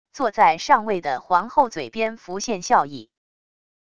坐在上位的皇后嘴边浮现笑意wav音频生成系统WAV Audio Player